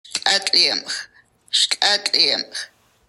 Listen to a pronunciation recording for St’át’imc.
Statimc-Lillooetpeople.m4a